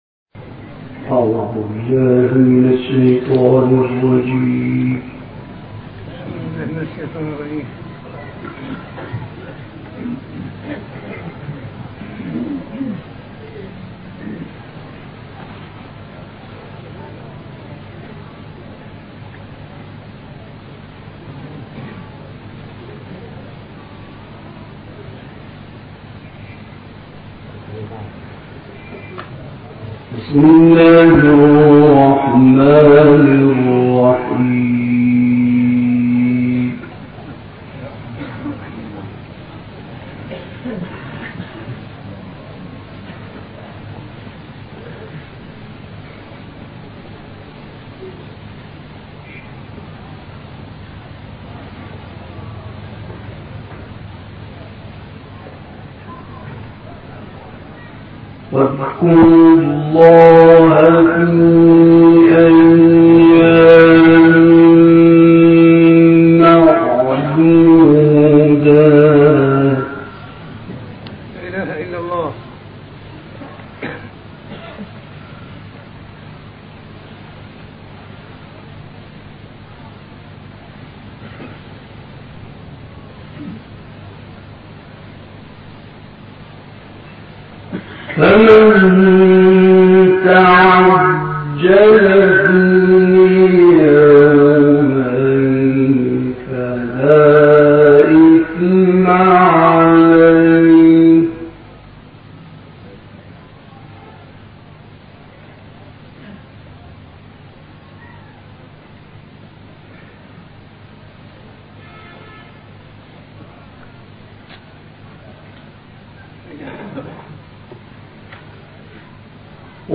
گروه شبکه اجتماعی: تلاوت آیاتی از سوره بقره در محفلی مشترک از حمدی محمود زامل و شکری البرعی را می‌شنوید.
محفل مشترک